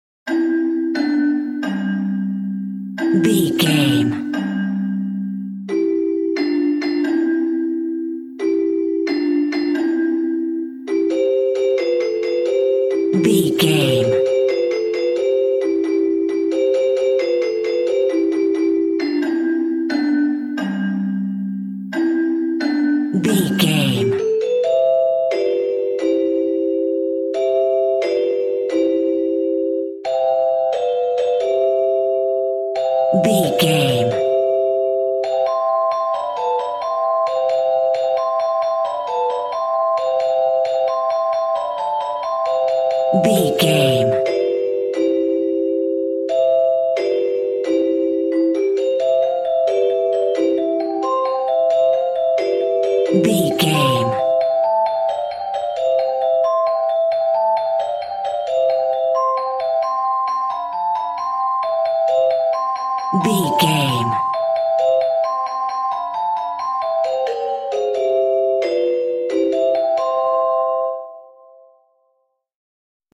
Ionian/Major
nursery rhymes
kids music